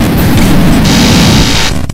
Glitch-Jumpscare.mp3